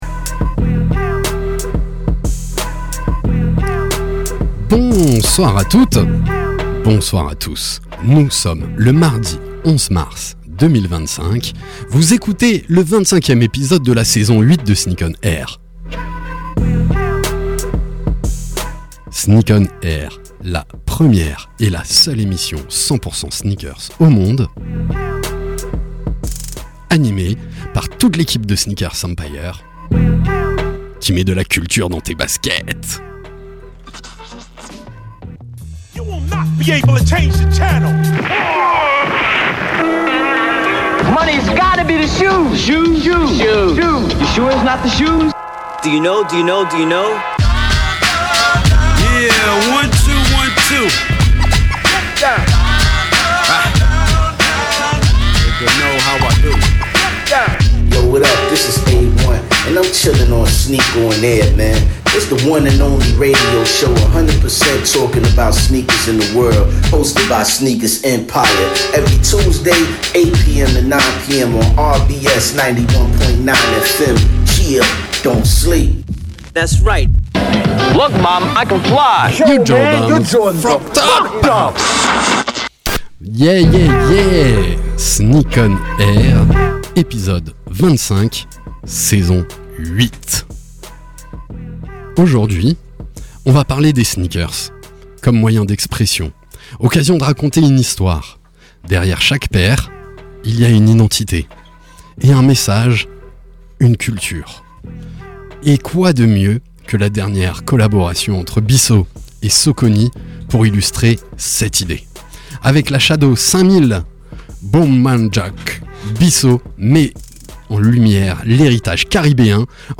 Sneak ON AIR, la première et la seule émission de radio 100% sneakers au monde !!! sur la radio RBS tous les mardis de 20h à 21h.
Actu sneakers, invités, SANA, talk.